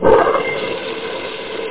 Amiga 8-bit Sampled Voice
1 channel
Flush.mp3